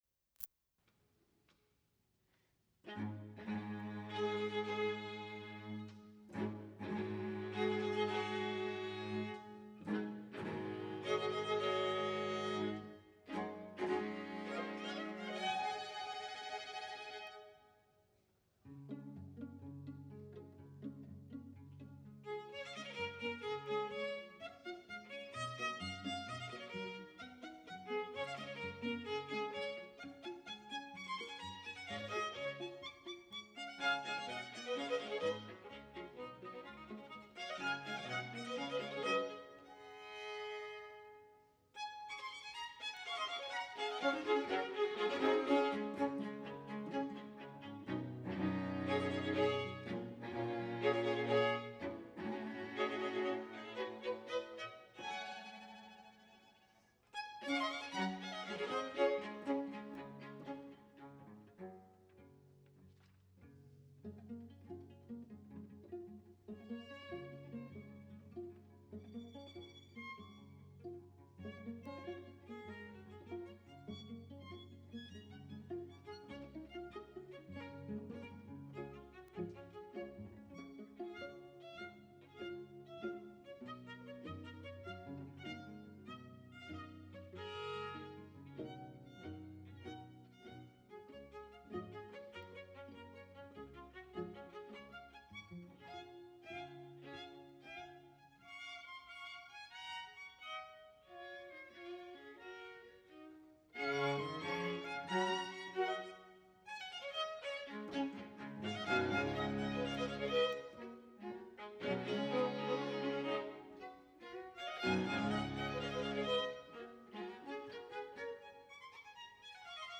for String Quartet (1996, rev. 2003)